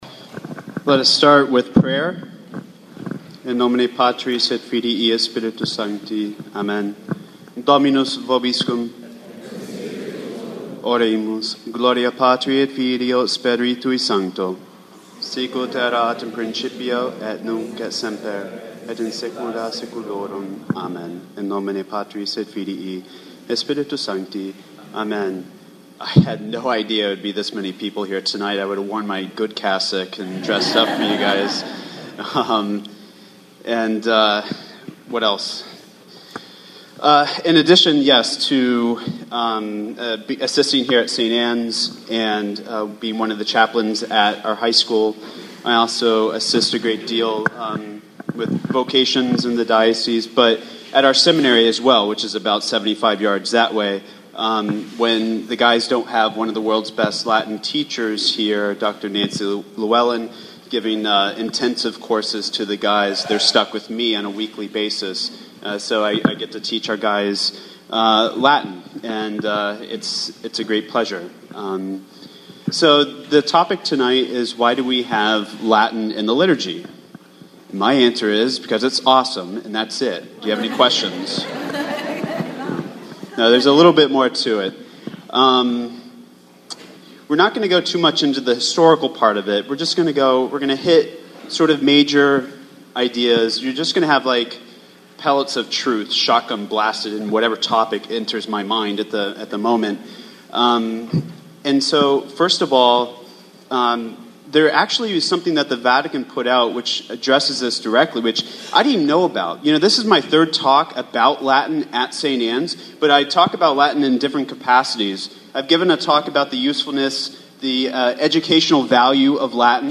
Technical Note: Although the talk is included in its entirety, due to technical error the video was lost for part of the talk.
No content was lost but the sound quality may be different during this segment.